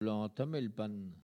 Collectif atelier de patois